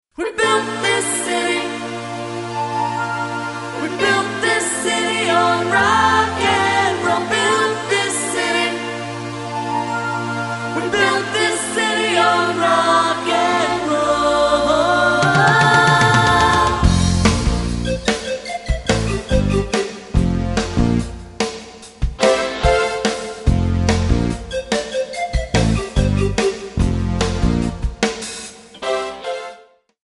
F#
MPEG 1 Layer 3 (Stereo)
Backing track Karaoke
Pop, Rock, Musical/Film/TV, 1980s